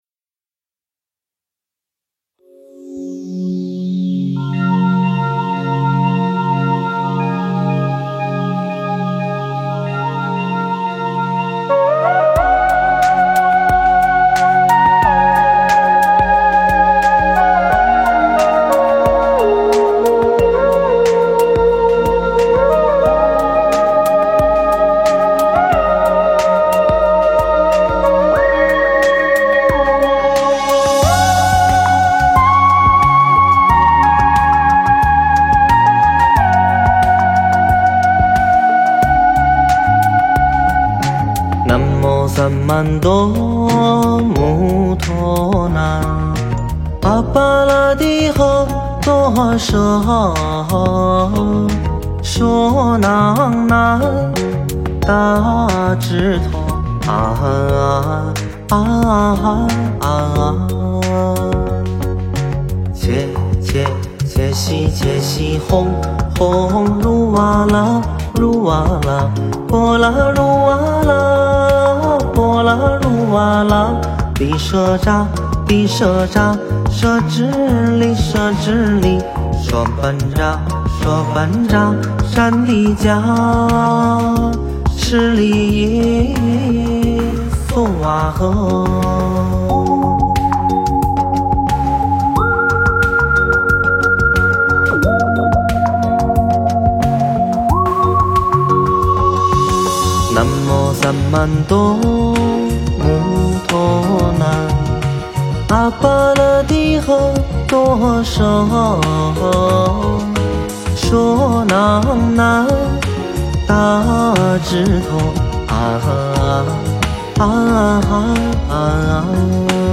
诵经
佛音 诵经 佛教音乐 返回列表 上一篇： 授记语 下一篇： 宗喀巴祈请颂 相关文章 南无阿弥陀佛(演唱